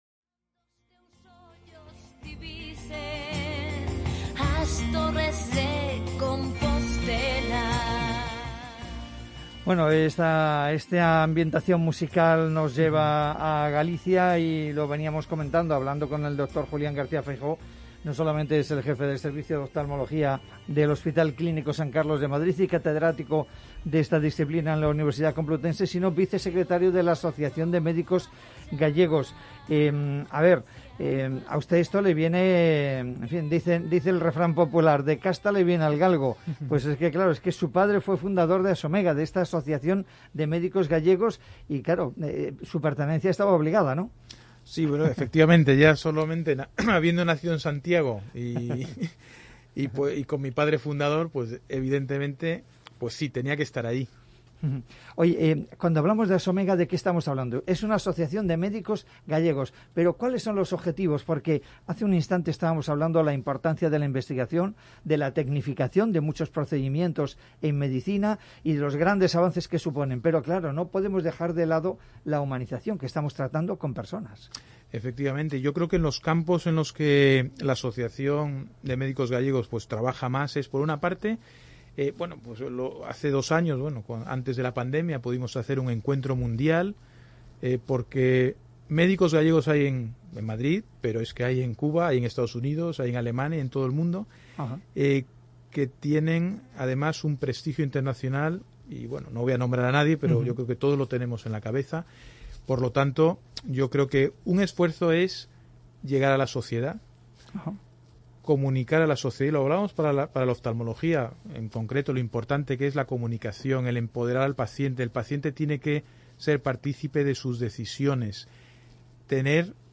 En el transcurso de la entrevista, en la que ha repasado algunas de las cuestiones clave y últimos avances de su especialidad, ha señalado que el miedo a acudir a un hospital ha llegado a producir pérdidas de visión irreparables en el caso de los pacientes con patologías previas o no detectadas que no han podido visitar a los especialistas. Así ocurre, por ejemplo, con la Degeneración Macular Asociada a la Edad, DMAE, para la que sí hay solución en un 5-10% de las formas leves.